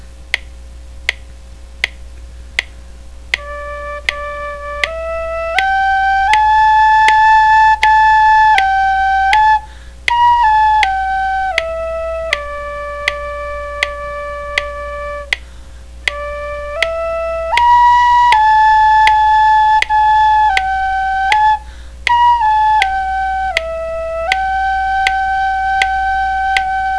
Click here to hear this tune played slowly                      Click here to hear this tune at full speed
slowrainyday.wav